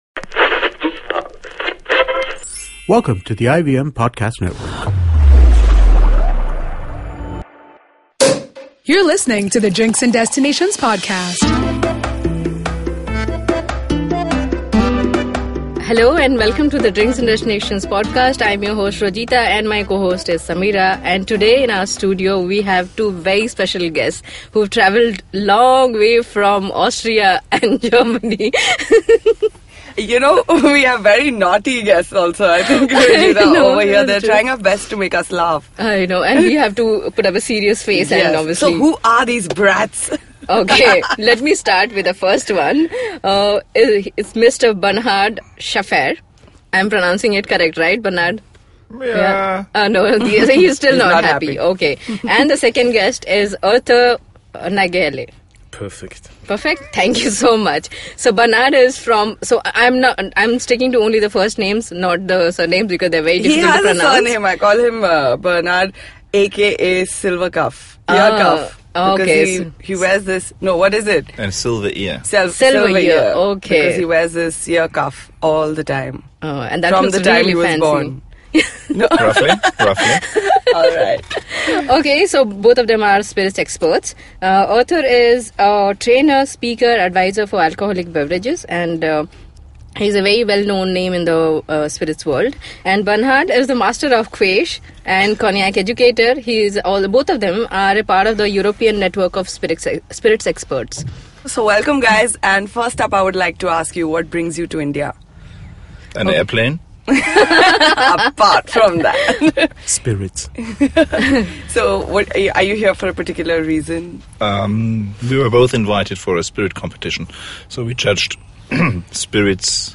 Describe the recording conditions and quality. in the studio about different kinds of spirits, how to judge spirits in a blind tasting competition, how are the spirits such as Gin and Absinthe produced etc. They also demystify the stories around Absinthe as a drink causing hallucinations and the legend that spread this misconception.